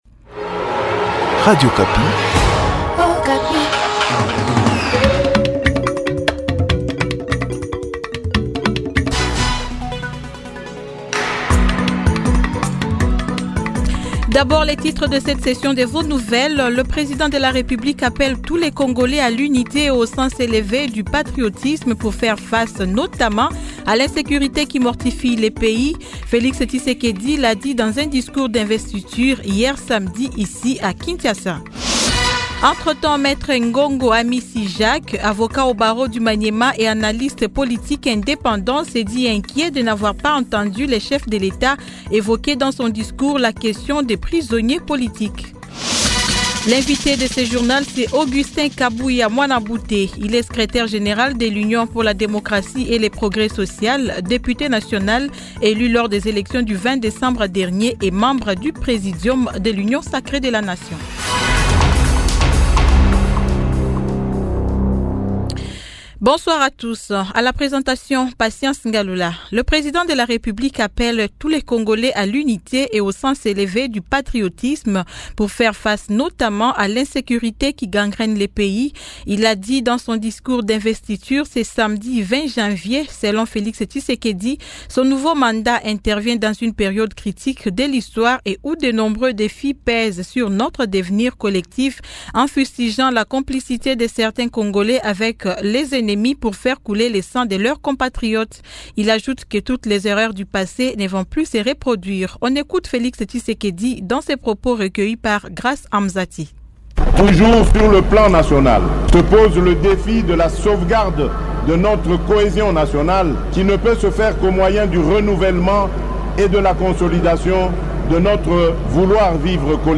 CONDUCTEUR JP SOIR DU DIMACHE 21 JANVIER 2024